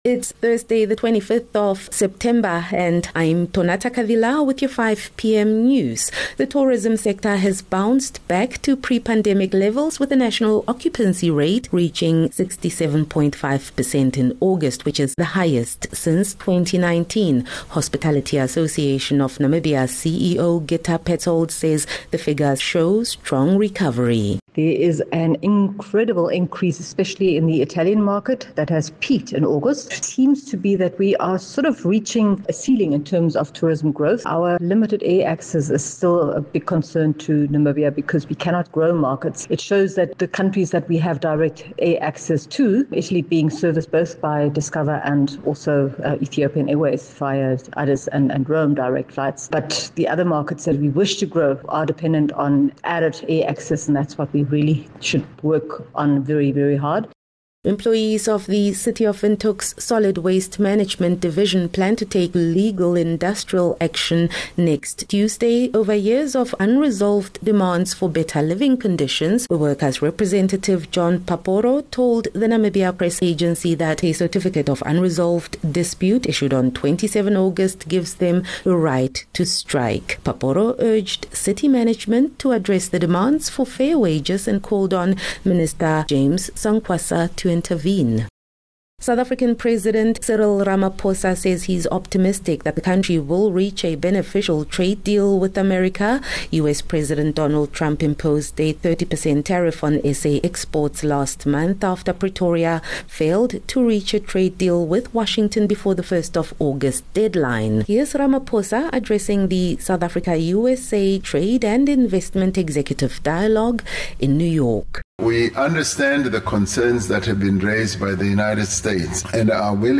25 Sep 24 September - 5 pm news